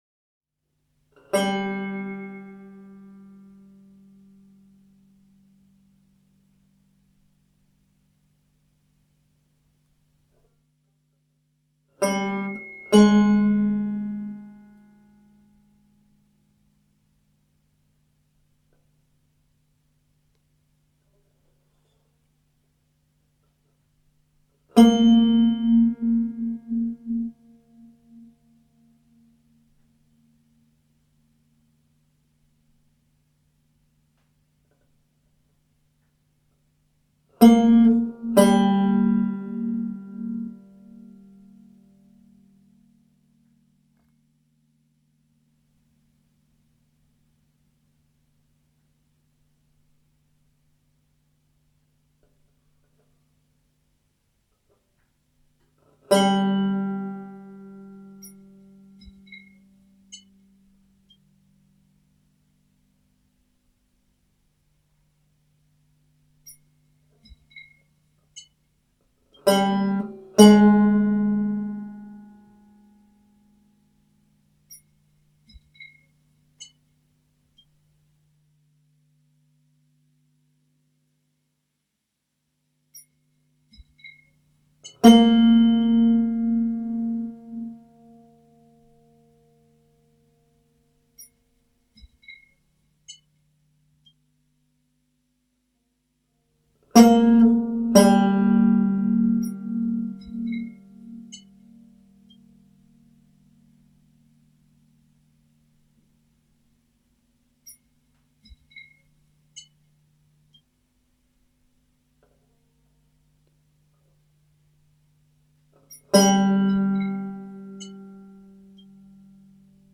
banjo
electronics
Raw, minimal banjo with self-built electronics.